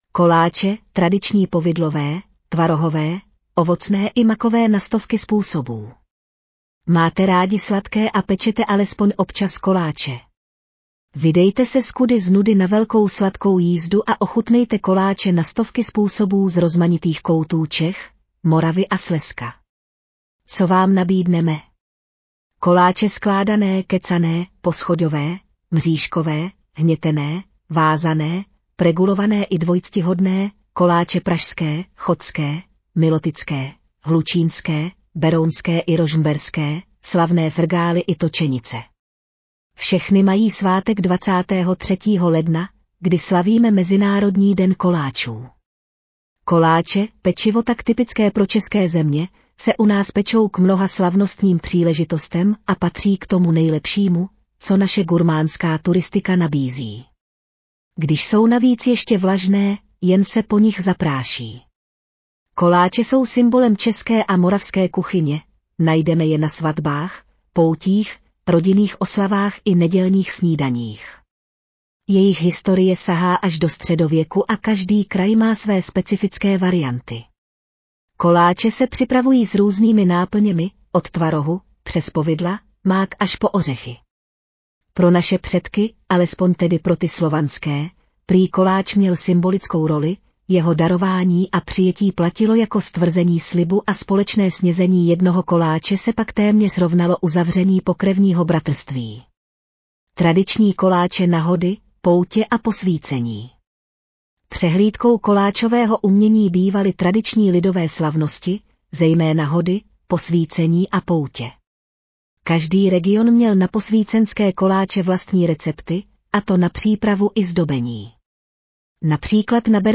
Audio verze článku Tradice: koláče na stovky způsobů na posvícení, svatby i pro domácí pečení